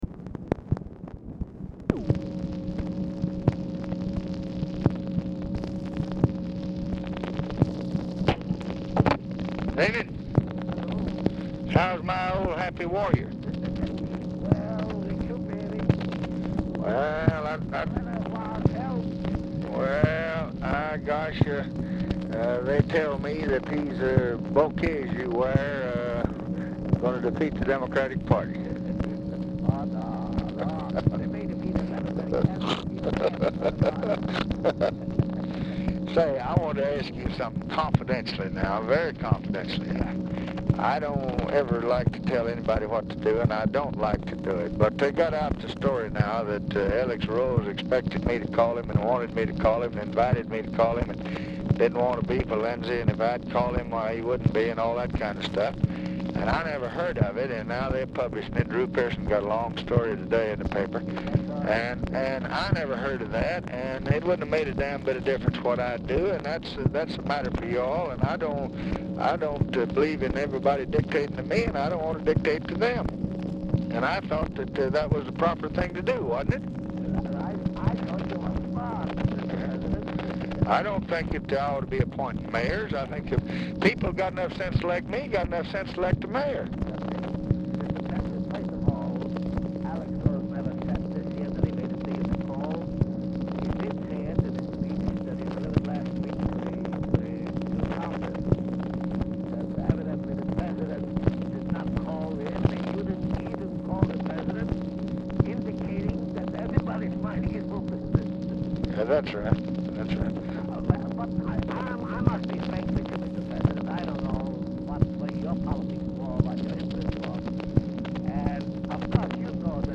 Oval Office or unknown location
POOR SOUND QUALITY; DUBINSKY IS ALMOST INAUDIBLE
Telephone conversation
Dictation belt